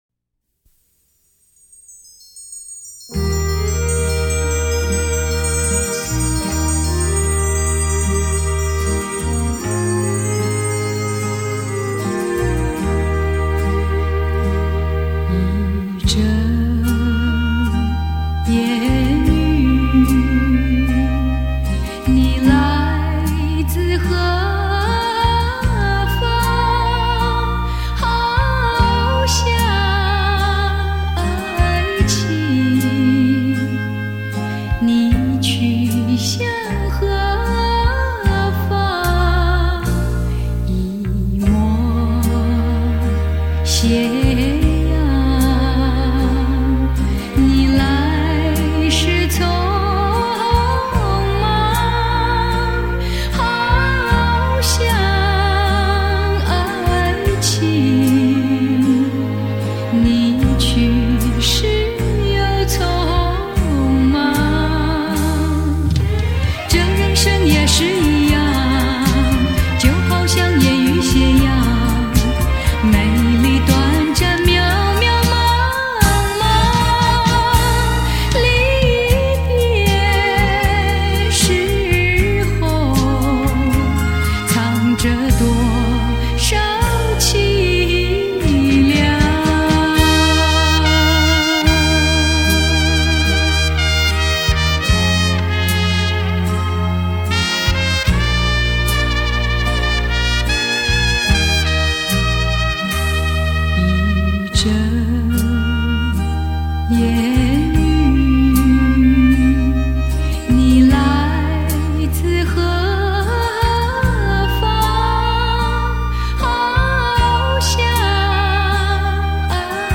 原始母带 24Bit 音质处理 发烧典藏